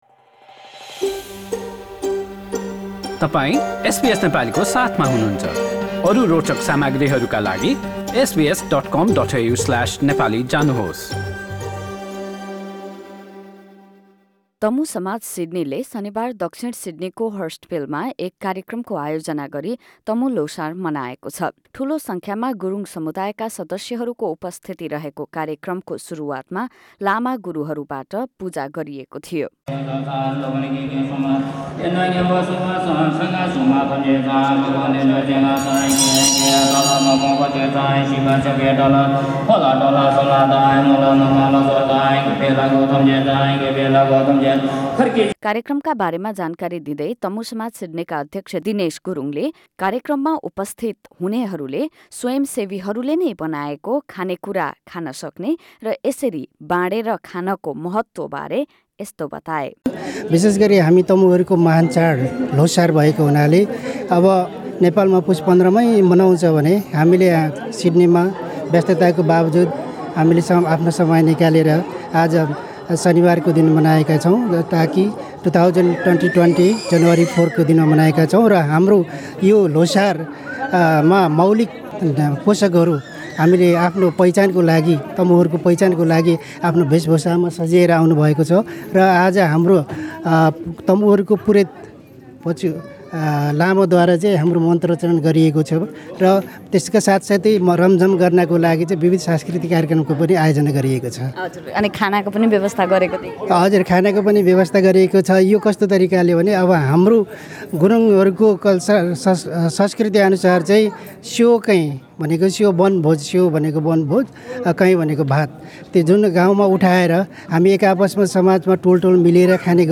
ल्होसार कार्यक्रमबारे पुरा रिपोर्ट माथिको मिडिया प्लेयरमा प्ले बटन थिचेर सुन्नुहोस्।